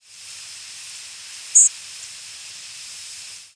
Indigo Bunting diurnal flight calls
Bird in flight with American Robin calling in the background.